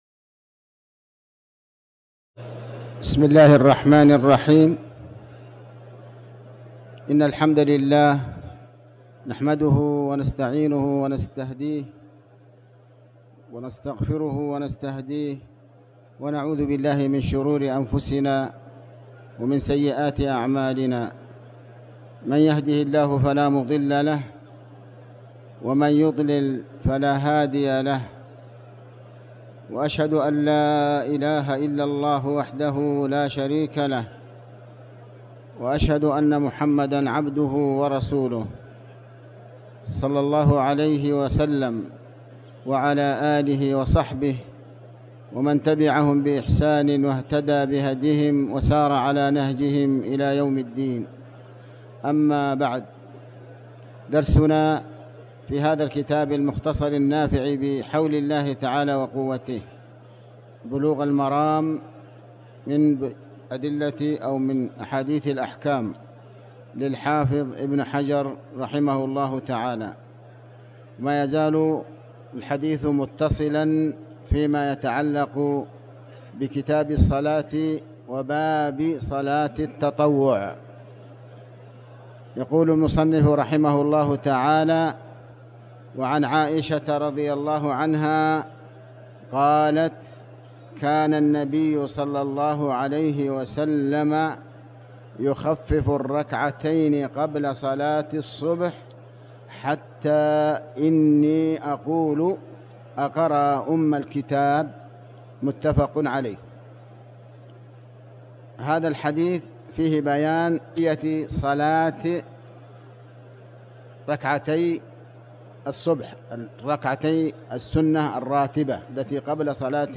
تاريخ النشر ١٨ جمادى الأولى ١٤٤٠ هـ المكان: المسجد الحرام الشيخ